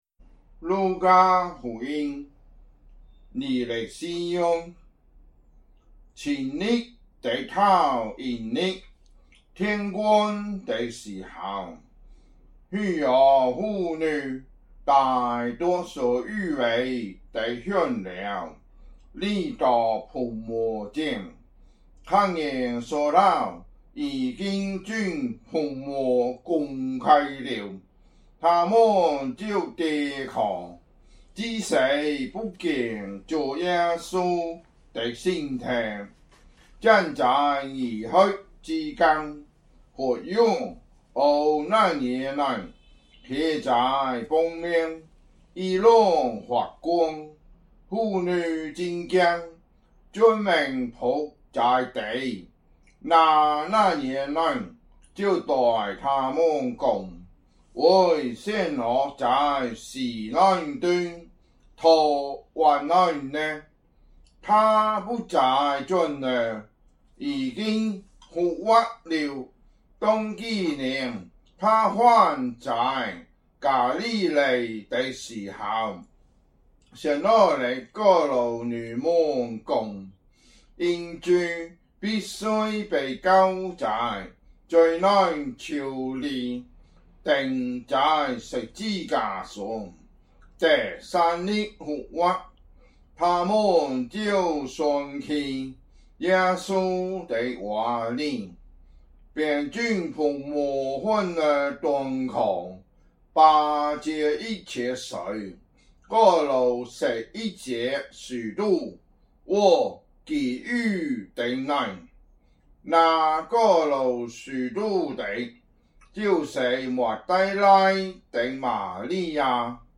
福州話有聲聖經 路加福音 24章